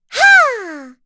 Dosarta-Vox_Attack1.wav